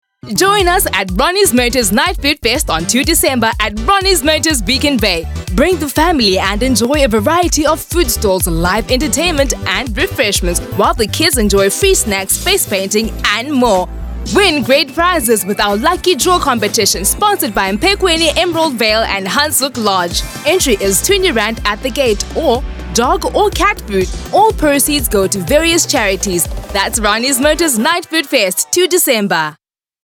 Vocal Styles:
clear, cultured, polished, youthful
My demo reels